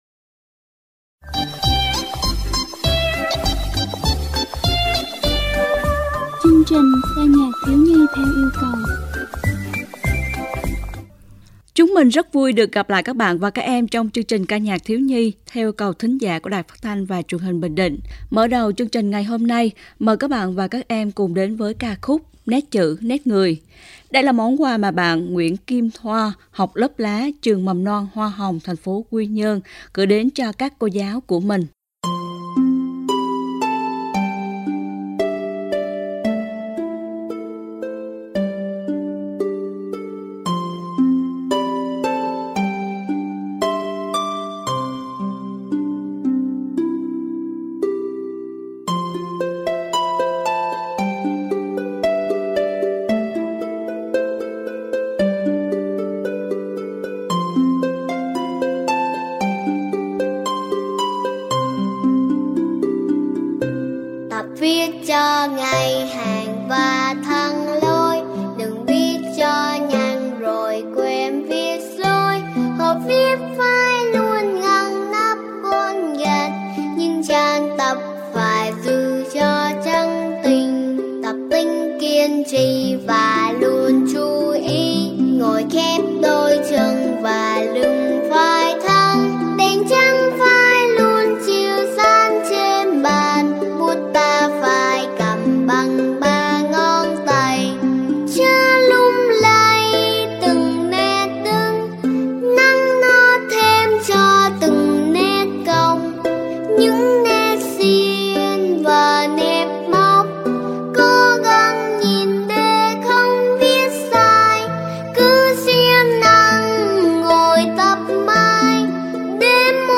8-5-ca-nhac-thieu-nhi-yeu-cau.mp3